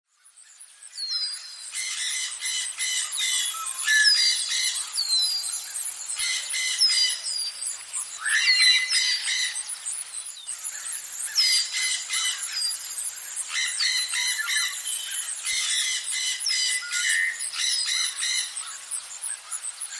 Jungle Birds
Jungle Birds is a free nature sound effect available for download in MP3 format.
289_jungle_birds.mp3